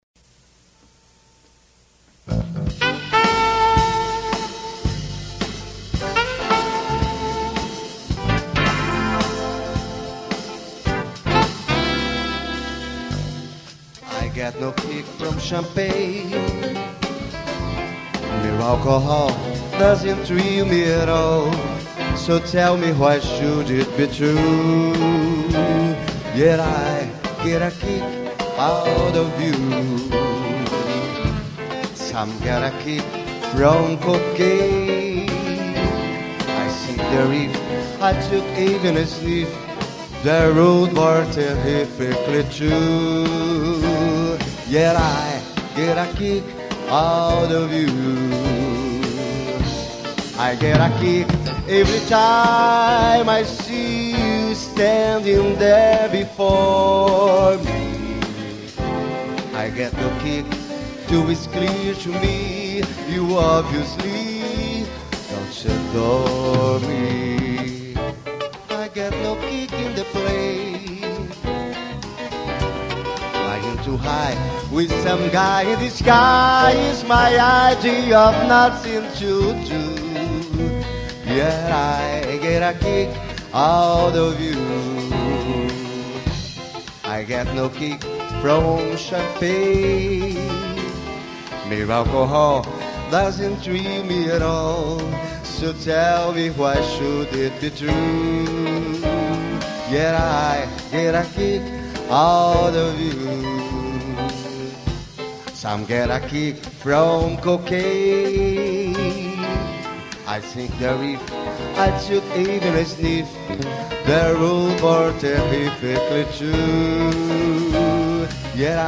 257   05:00:00   Faixa:     Jazz